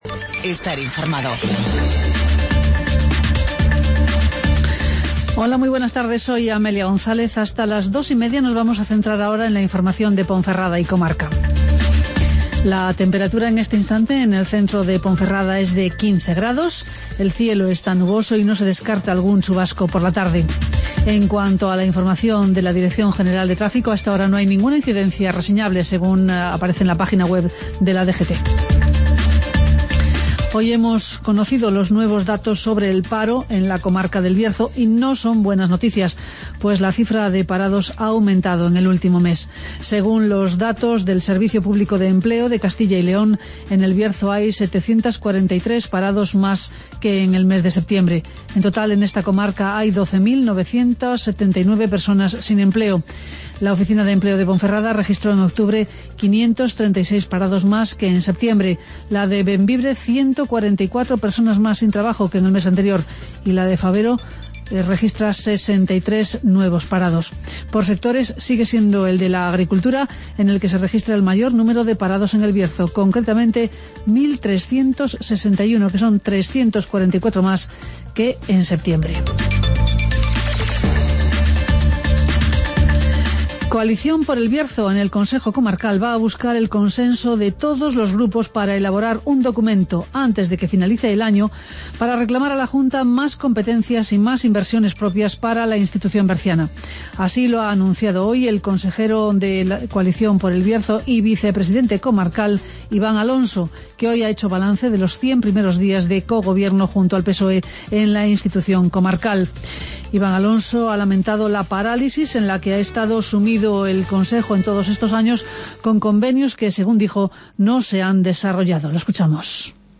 Escucha las noticias de Ponferrada y comarca en el Informativo Mediodía de COPE Bierzo